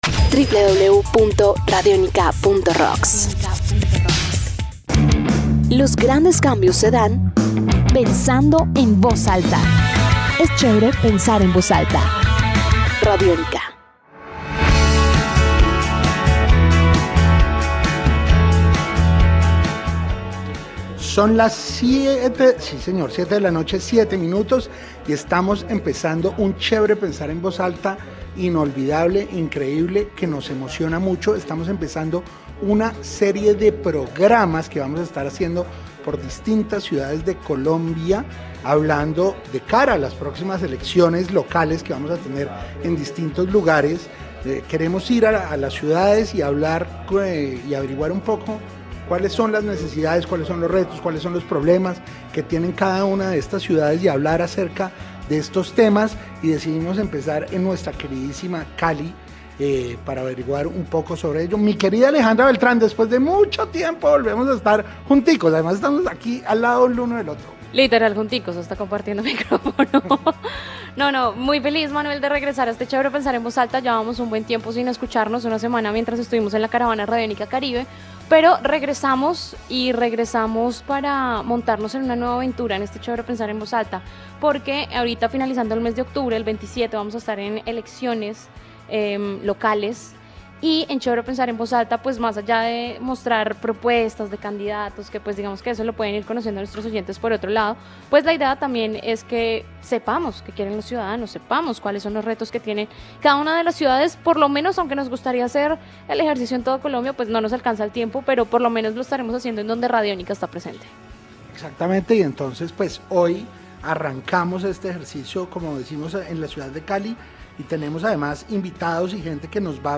CALI: Seguridad y Cultura Nuestro primer aterrizaje en Chévere Pensar en Voz Alta con mira a las elecciones regionales del 27 de octubre fue en la ciudad de Santiago de Cali .